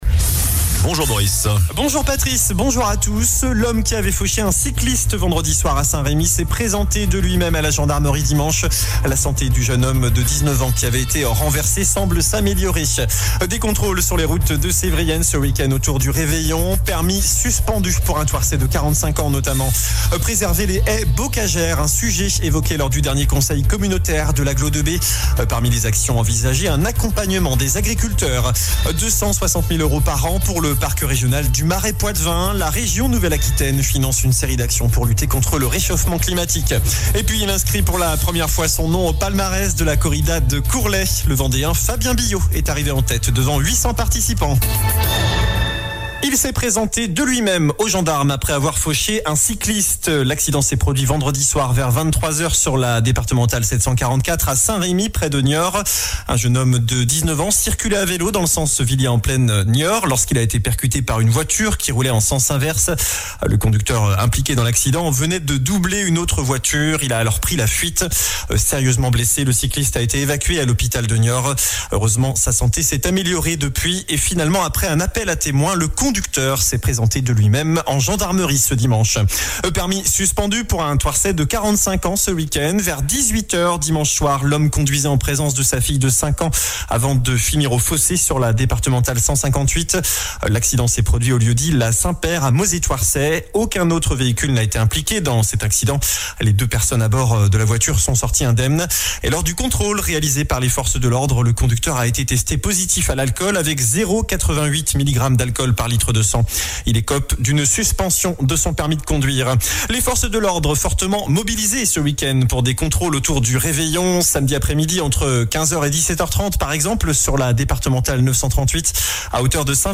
JOURNAL DU MARDI 26 DECEMBRE ( MIDI )